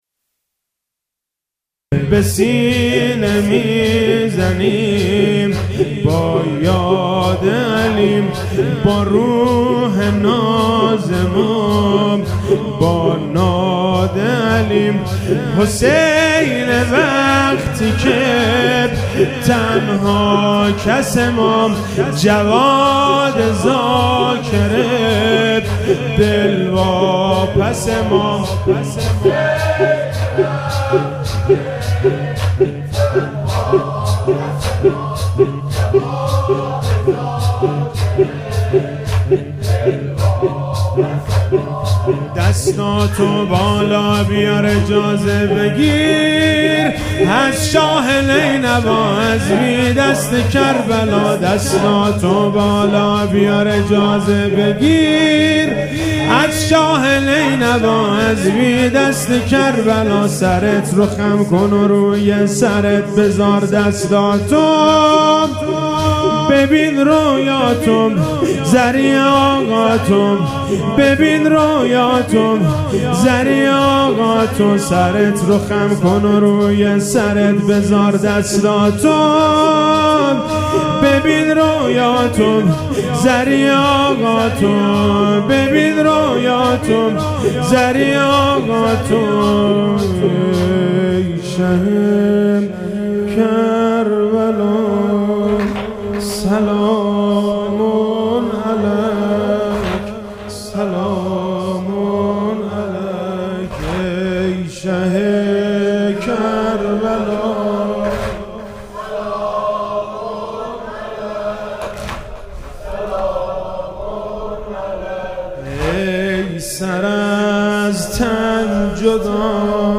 مداحی جدید
شب چهارم محرم 1399 هیئت انصار الحجه مشهد